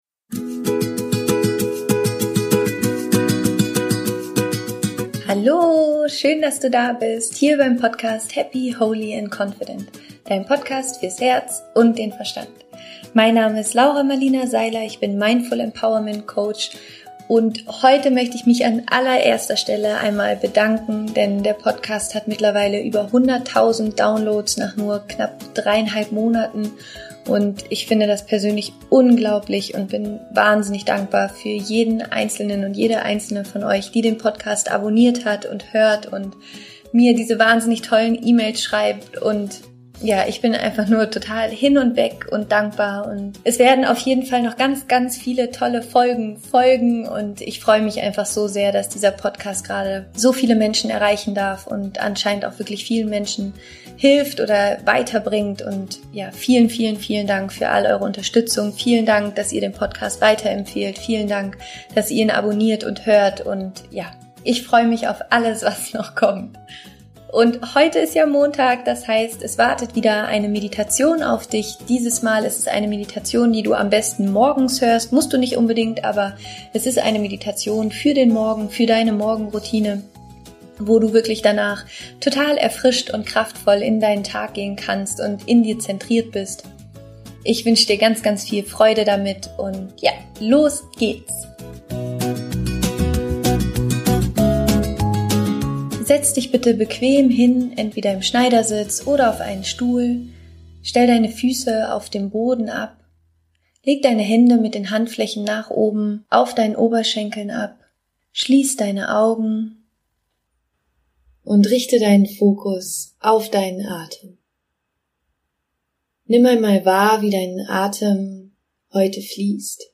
Erfrischt in deinen Tag starten - Meditation